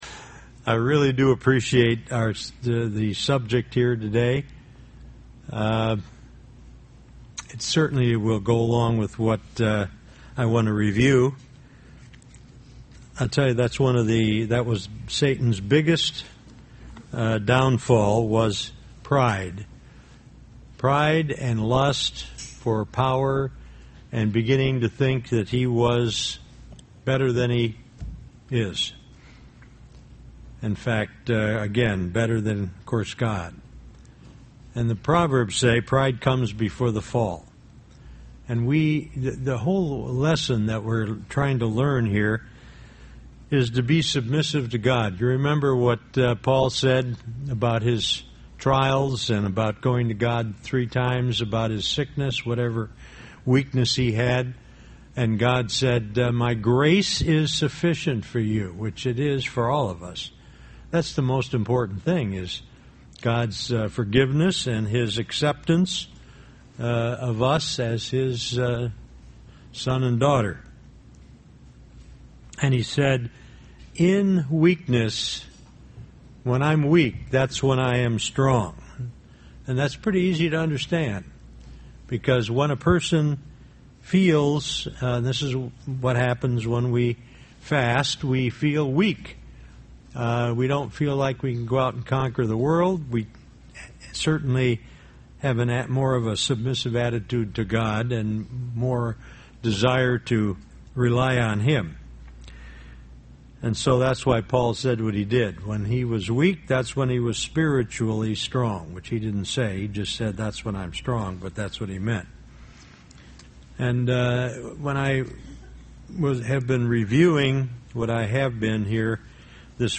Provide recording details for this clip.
Given in Beloit, WI